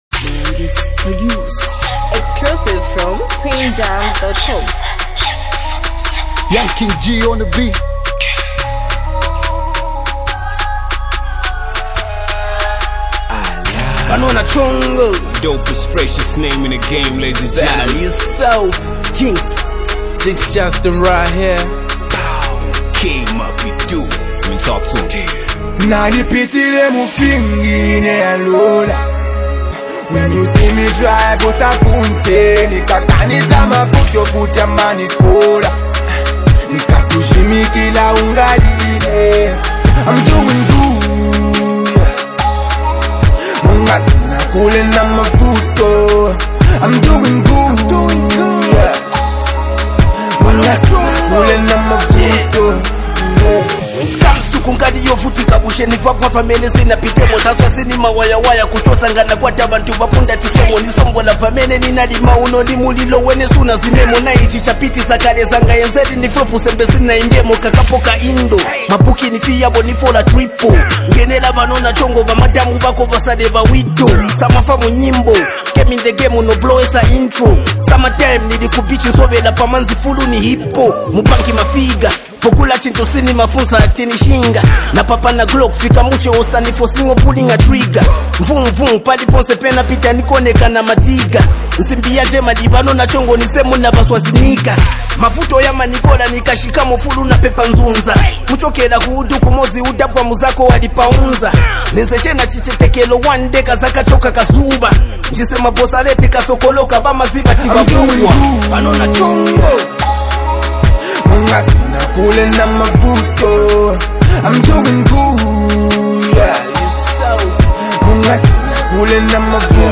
Zambian hip-hop and street anthem energy